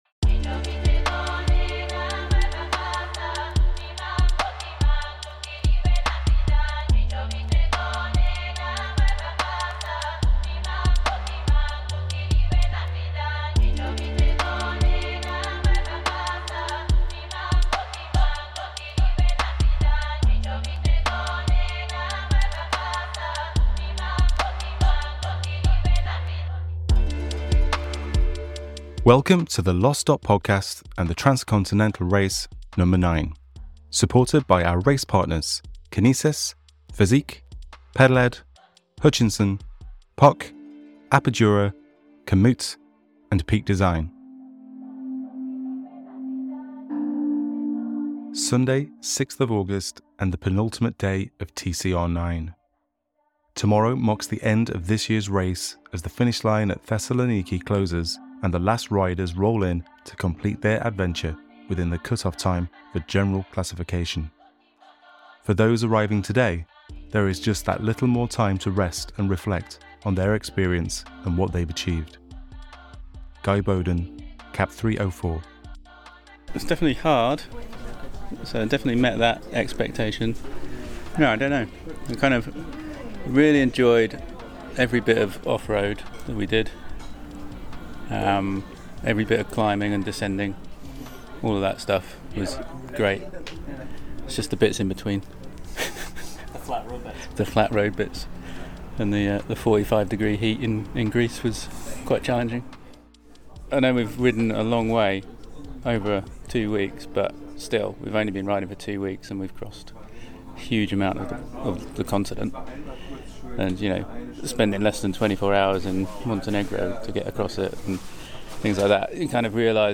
In this final TCRNo9 episode we hear from more Race finishers after they've completed their journeys at the Finish in Thessaloniki.